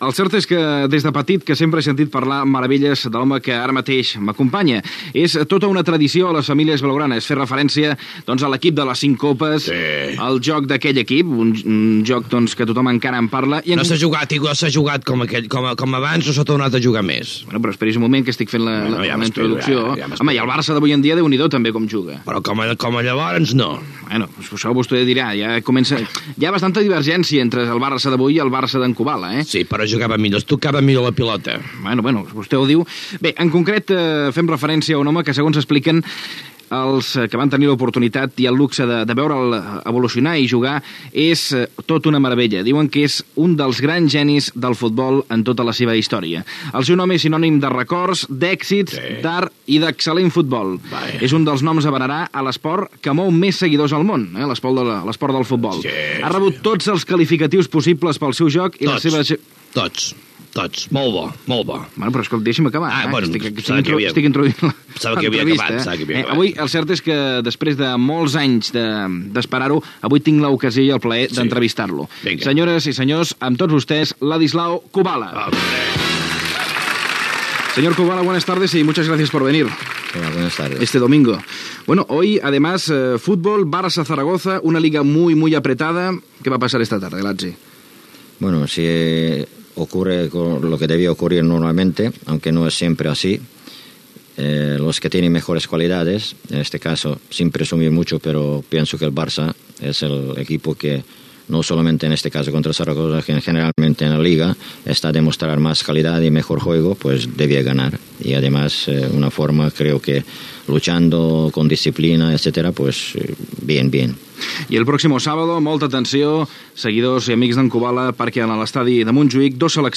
Entrevista al futbolista Ladislao Kubala.
FM